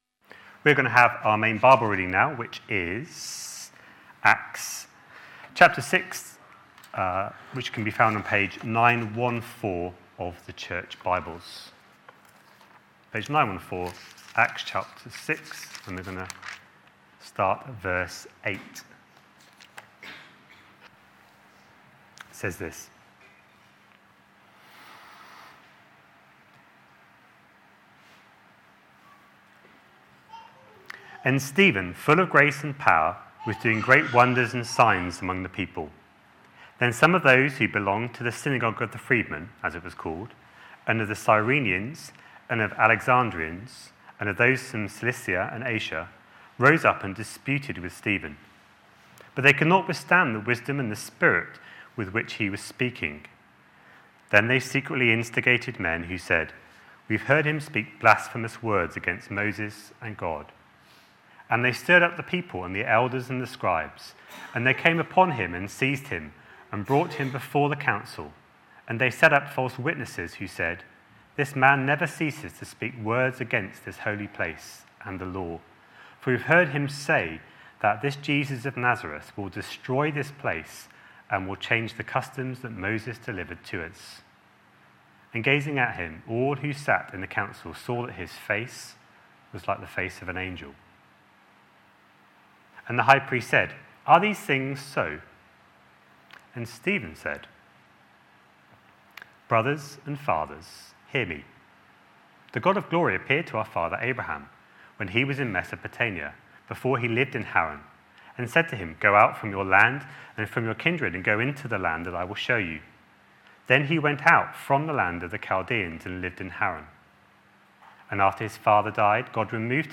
A sermon preached on 29th September, 2019, as part of our Acts series.